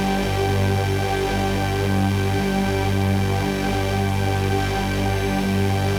Index of /musicradar/dystopian-drone-samples/Non Tempo Loops
DD_LoopDrone5-G.wav